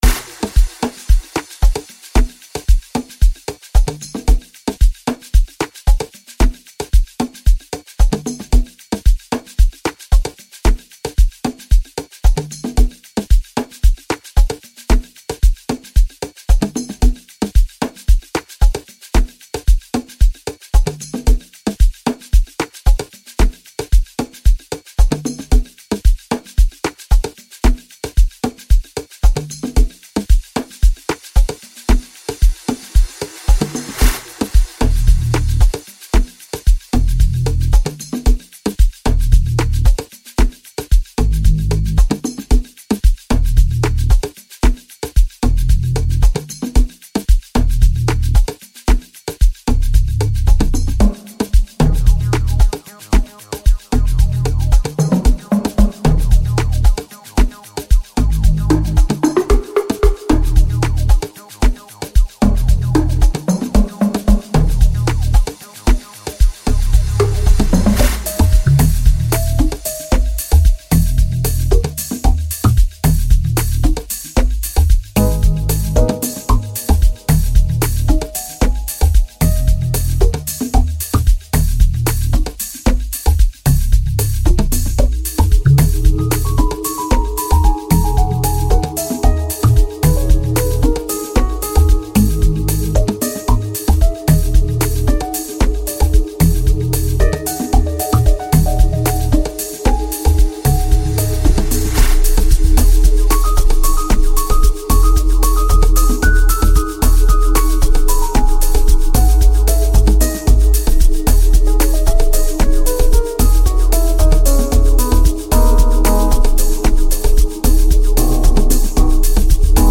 Amapiano
This soulful and melodic composition